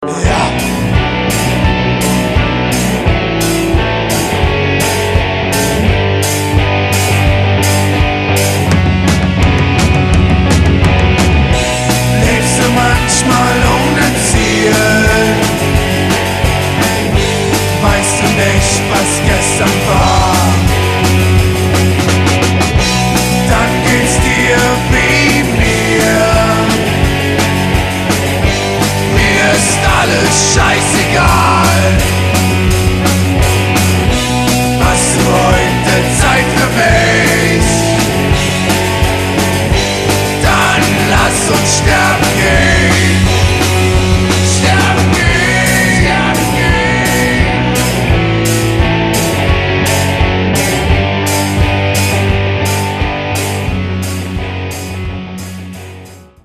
einen sehr melodiösen aber harten Sound
Streetrock mit Oi! & Punkeinflüssen und Bombast-Chören,
verpackt in knackige Ohrwürmer-Refrains!